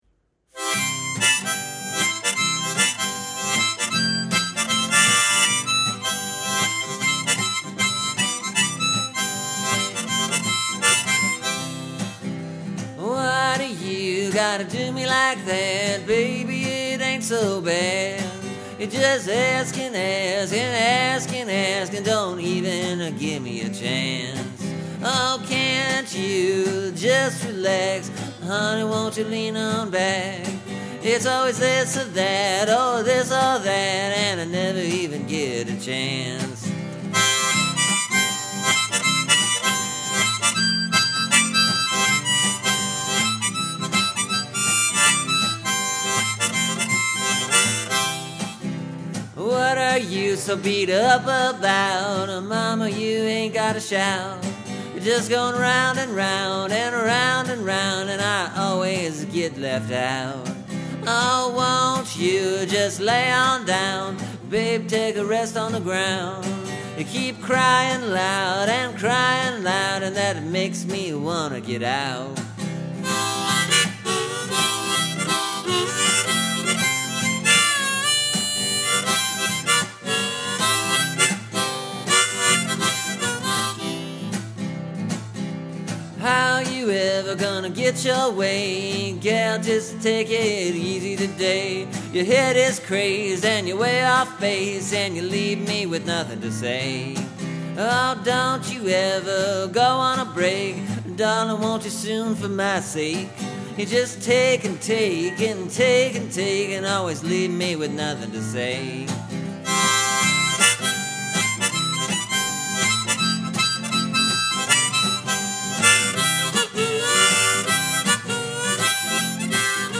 The song moves. Doesn’t seem to boring or repetitive or anything.